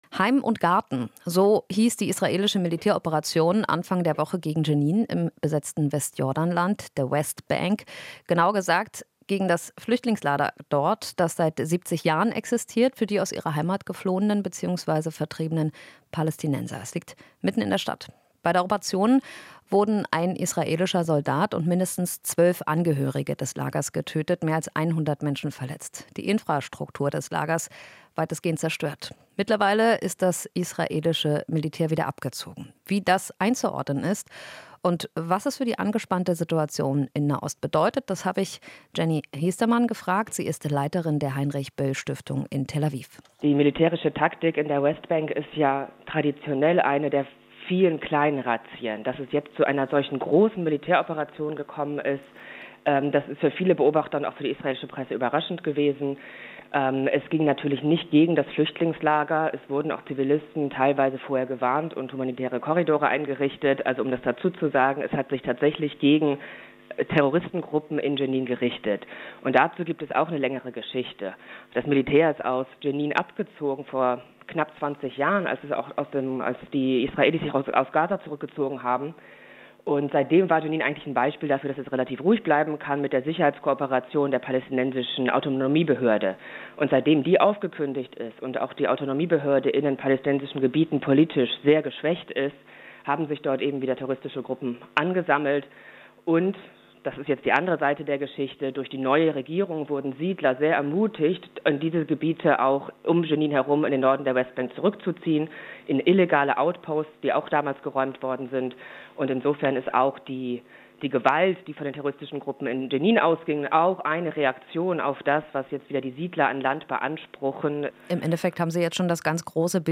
Interview - Israel-Expertin: Nahost-Konflikt mitten in der Eskalation